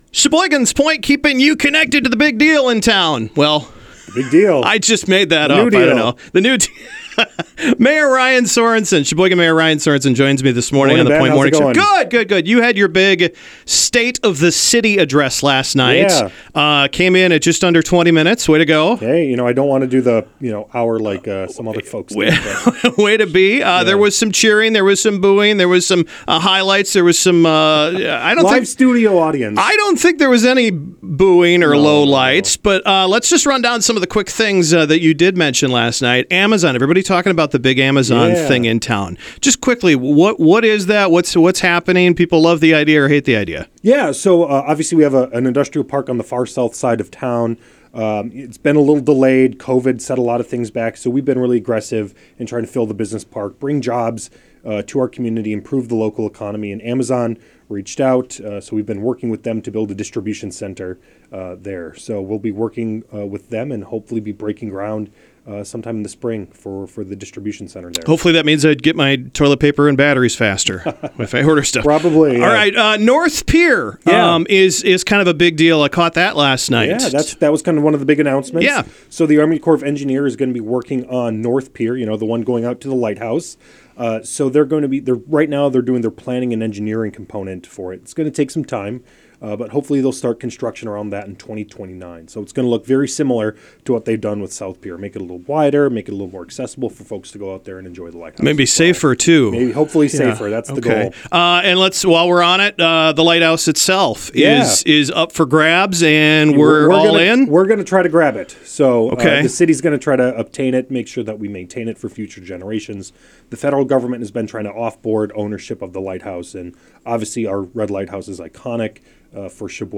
Catch the full interview from The Point Morning Show and get up to speed on what’s happening around Sheboygan.